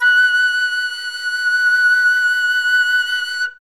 51c-flt24-F#5.wav